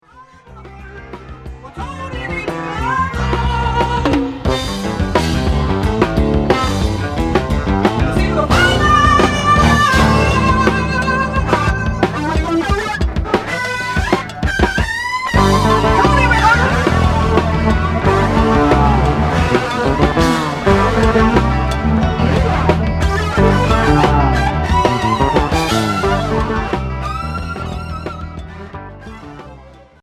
Progressif fusion Unique 45t retour à l'accueil